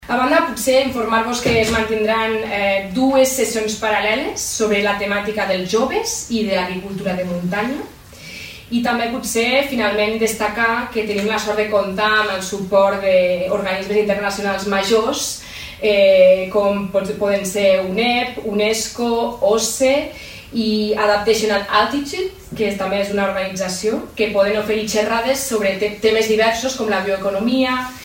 També es preveuen sessions paral·leles centrades en el paper dels joves i en l’agricultura de muntanya per compartir experiències i bones pràctiques. N’ha donat més detalls l’ambaixadora per a la Cooperació Transfronterera, Noelia Souque.